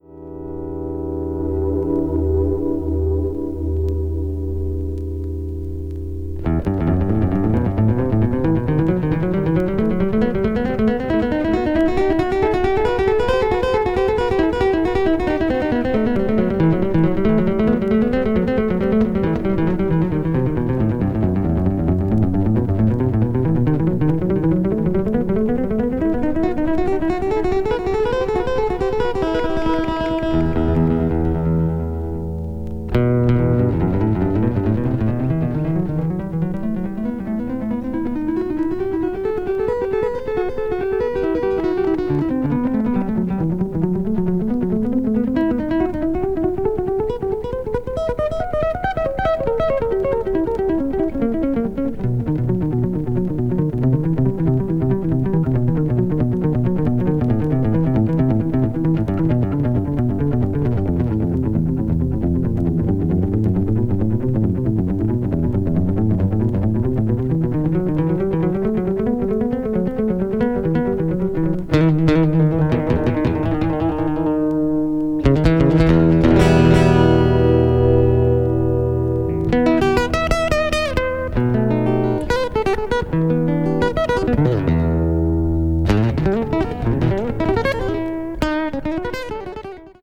B1のエフェクティヴなギター・ソロが宇宙的。